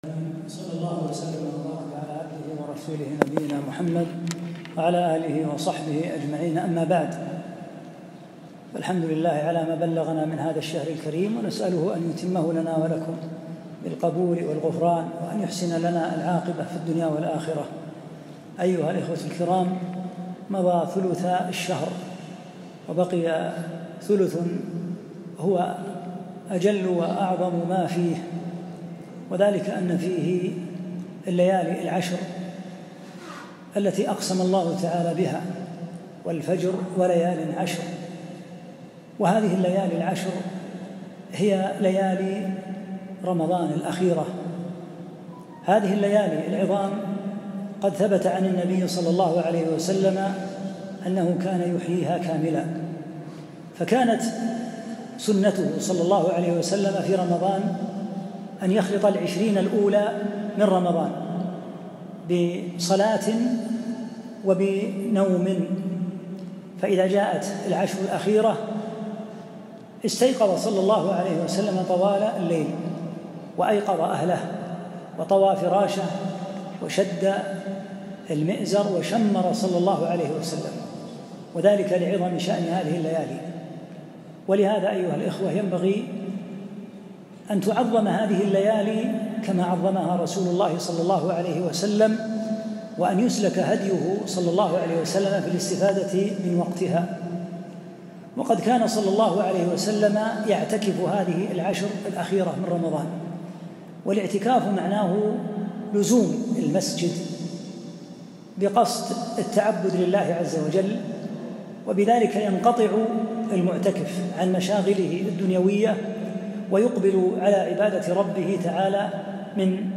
(صوت - جودة عالية